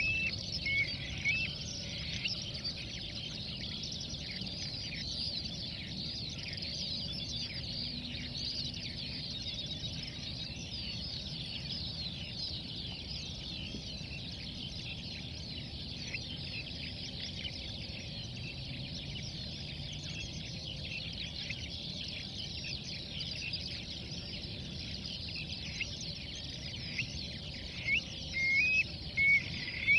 Œdicnème criard - Mes zoazos
oedicneme-criard.mp3